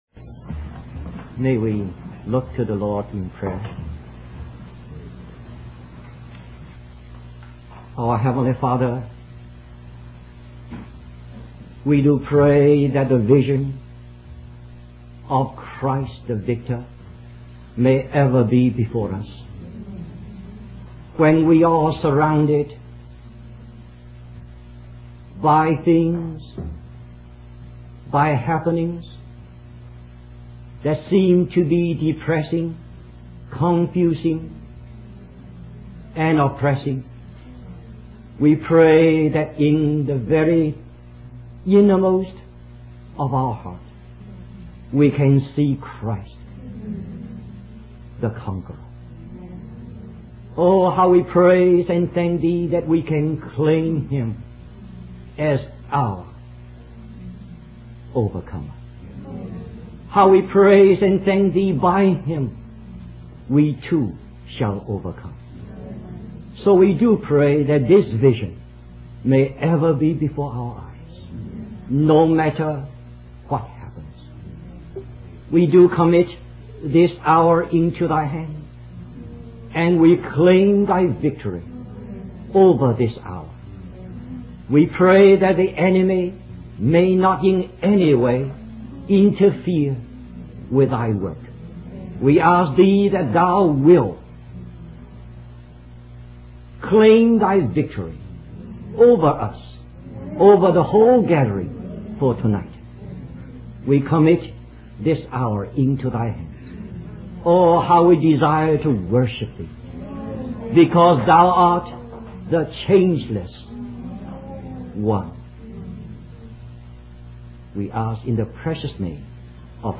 A collection of Christ focused messages published by the Christian Testimony Ministry in Richmond, VA.
Wabanna (Atlantic States Christian Convocation)